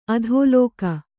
♪ adhōlōka